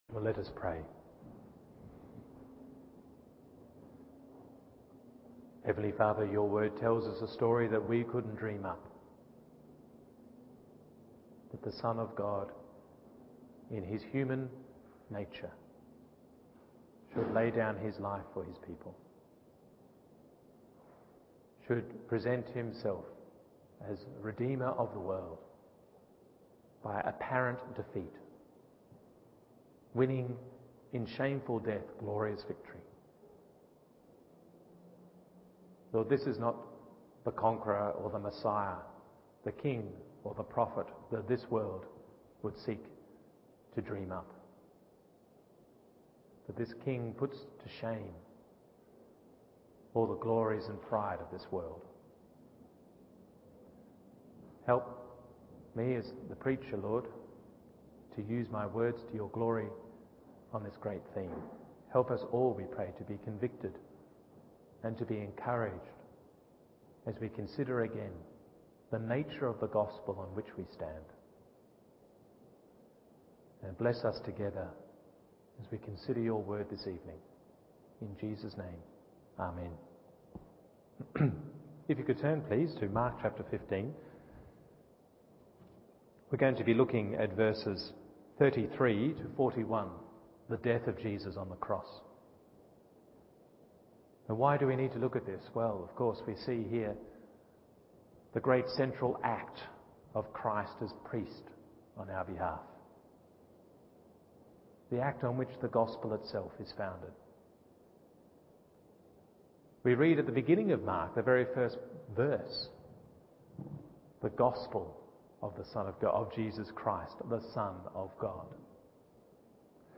Evening Service Mark 15:33-41…